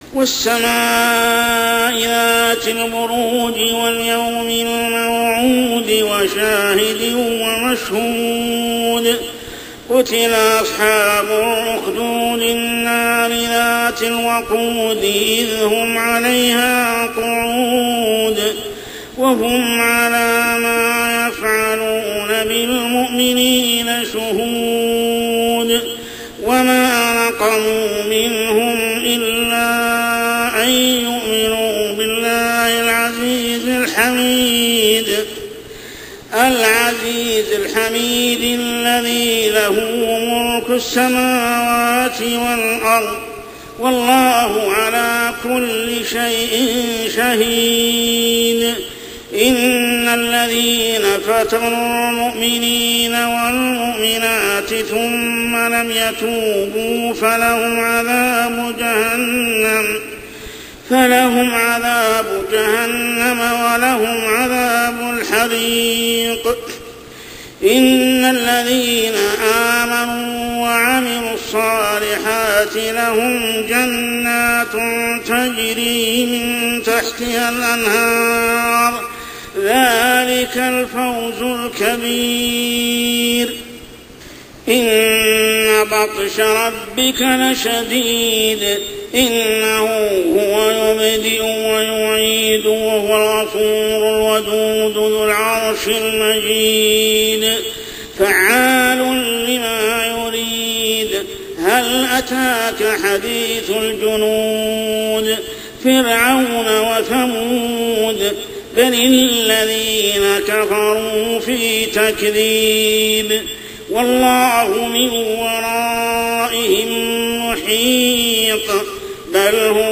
عشائيات شهر رمضان 1426هـ سورة البروج كاملة | Isha prayer Surah AL-BURUJ > 1426 🕋 > الفروض - تلاوات الحرمين